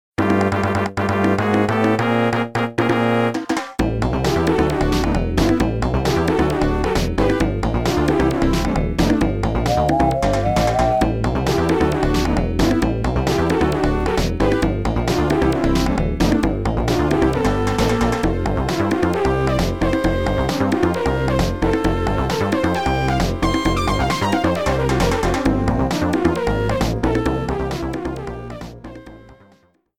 Background music
Reduced length to 30 seconds, with fadeout.